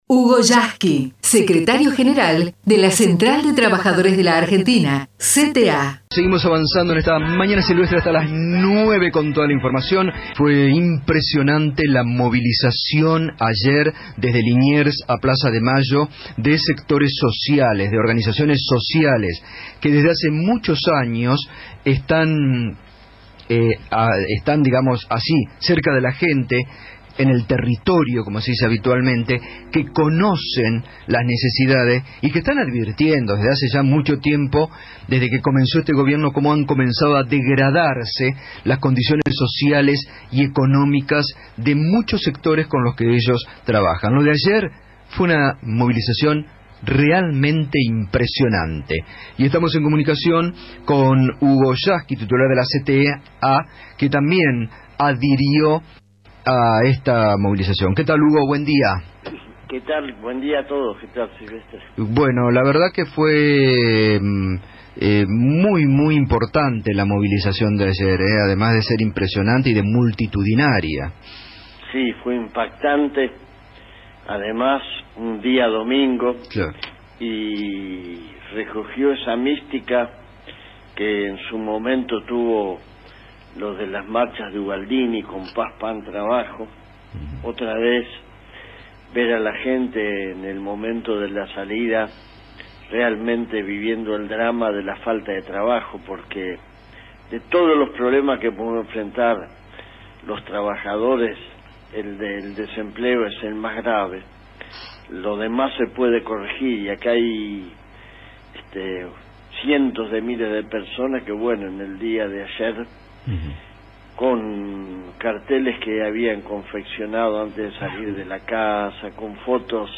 HUGO YASKY // entrevista Radio Del Plata - Gustavo Sylvestre